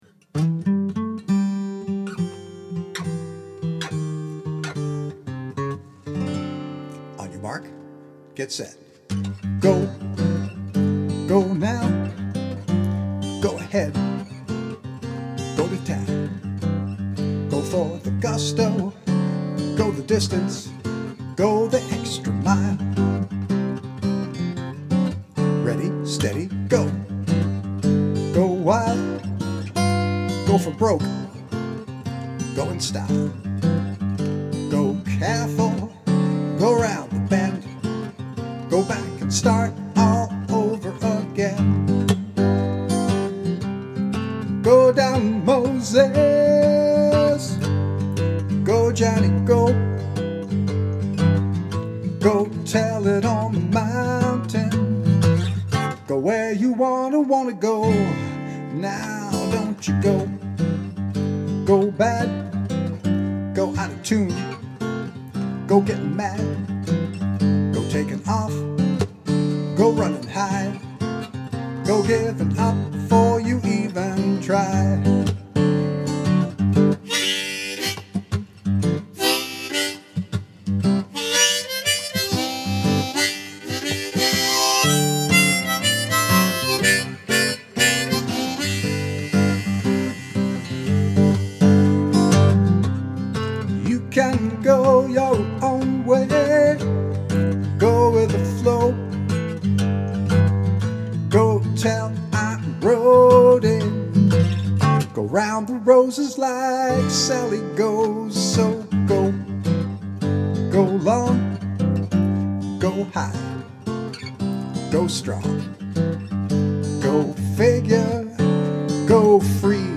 In August of this year, I finally fixed the rather pesky third verse and recorded “Go!” with the addition of a harmonica solo as the finishing touch.